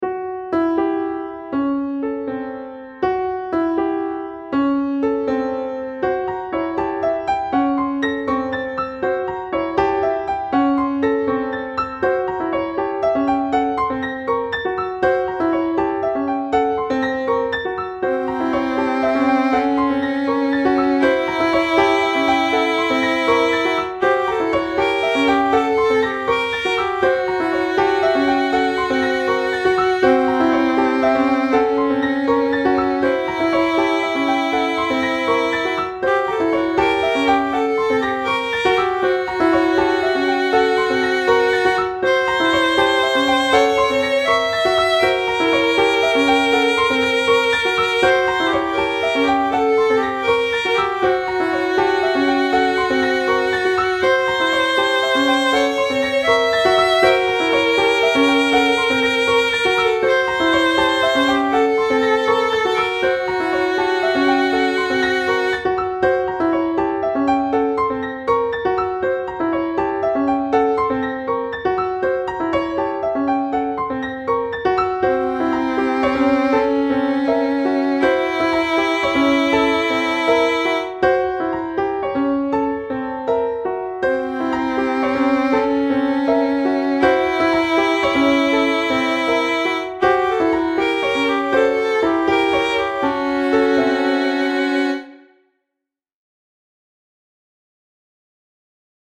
Pieza contemporánea para piano y violín
violín
piano
melodía
rítmico